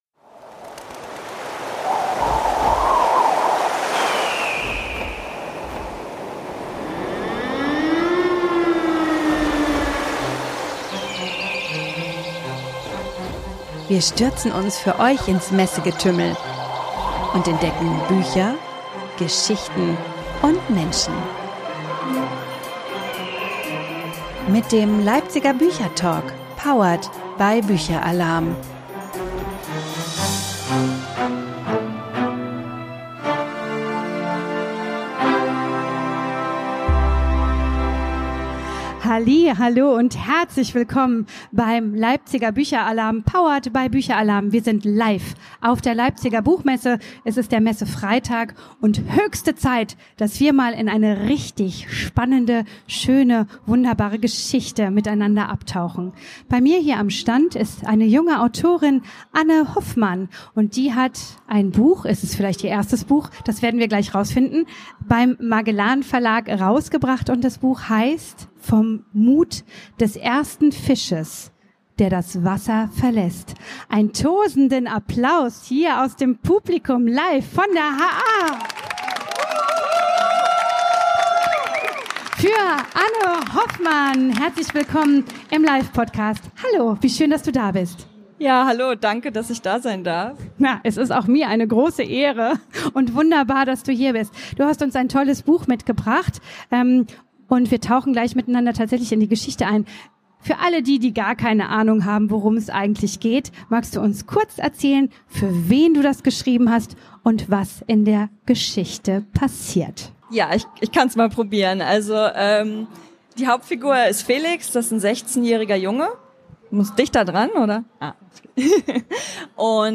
Coming-of-Age trifft auf Mystery: wir tauchen mit der Autorin in die Geschichte ein und bekommen einen Einblick in ihren ganz persönlichen Werdegang. Eine Geschichte zwischen Pflichtbewusstsein, Wunsch nach Zugehörigkeit und Geisterbeschwörungen.